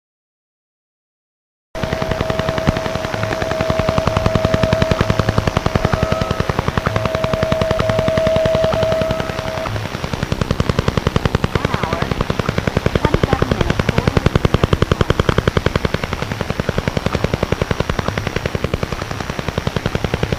Short audio clip of the Russian “Woodpecker” from the late 1970s.
However in its day, the old style helicopter blade thumping sound was produced by a 10 Megawatt (ERP) transmitter spanning 40 kHz with a repetition frequency of 10 Hz.
Russian-Woodpecker-narrow-audio-bandwidth-recording-of-wideband-Russian-Over-the-Horizon-RADAR-sometime-in-the-1970s.mp3